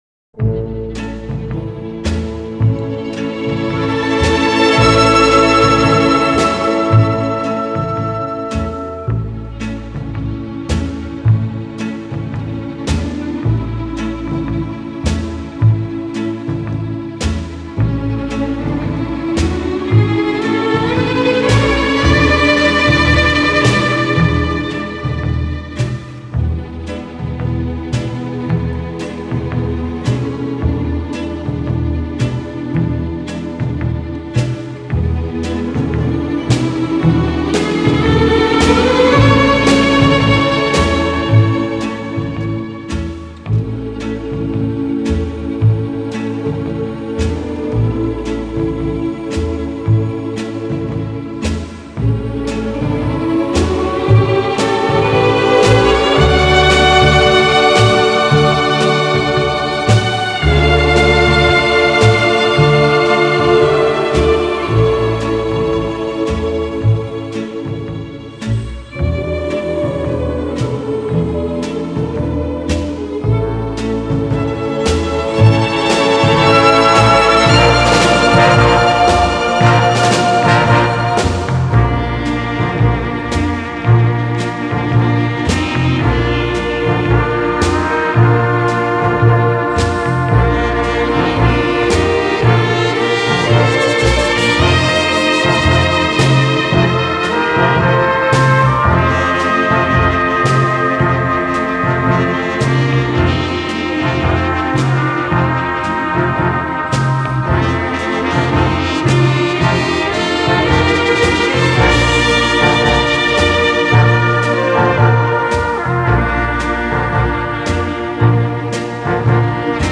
Genre: Jazz, Pop
Style: Easy Listening, Swing